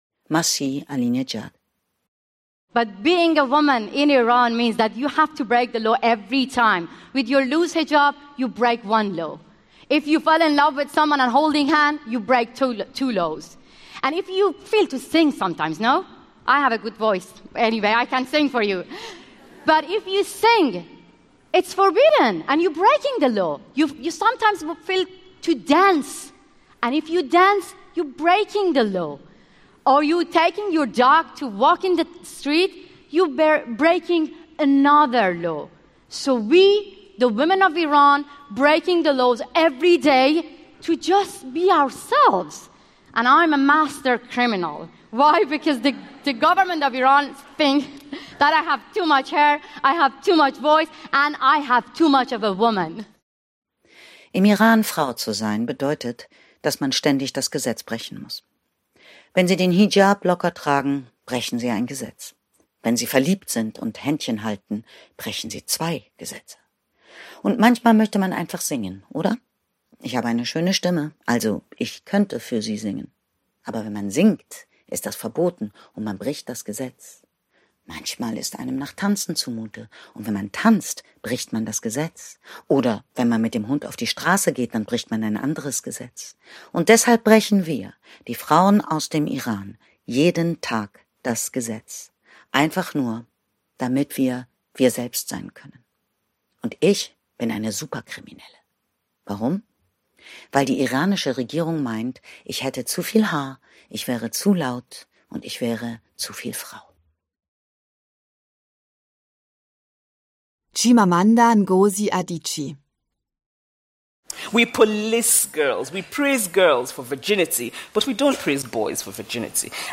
Melanie Raabe, Katja Riemann (Sprecher)
2025 | Ungekürzte Lesung, Anthologie
Als Hörbuchsprecherin überzeugt sie mit ihrer schönen Stimme und ihrer klugen Interpretation.